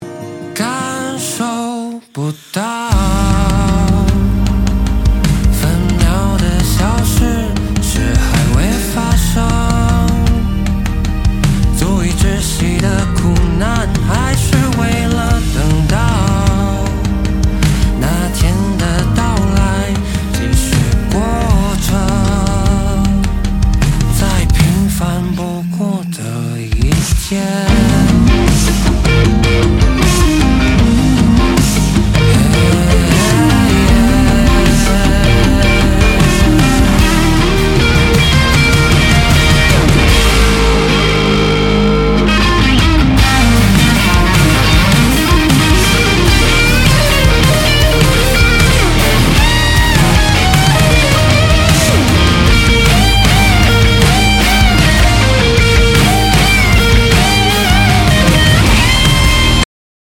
Alternative rock, Chinese pop